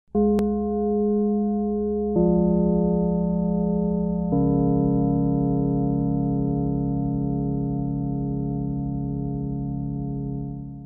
Hier gibt es den Kinogong und mit einem Klick auf das Plakat auch noch den Filmtrailer auf YouTube.
gong.mp3